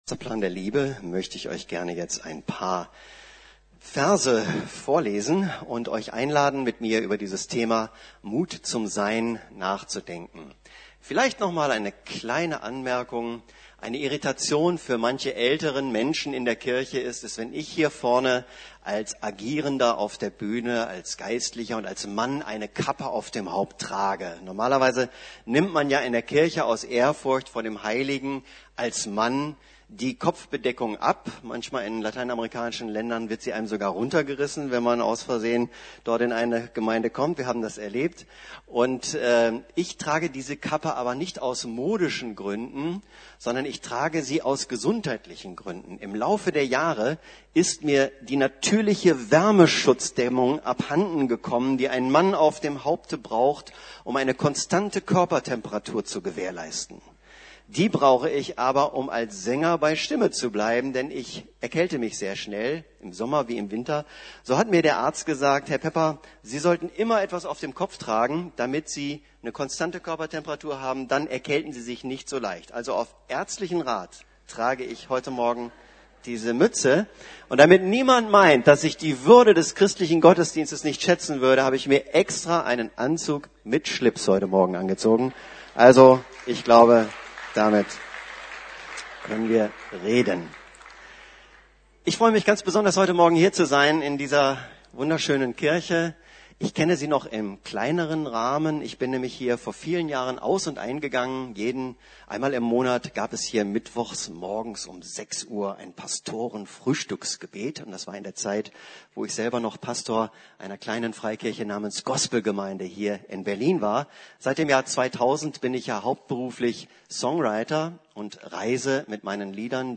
Mut zum Sein ~ Predigten der LUKAS GEMEINDE Podcast